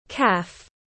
Caff /kæf/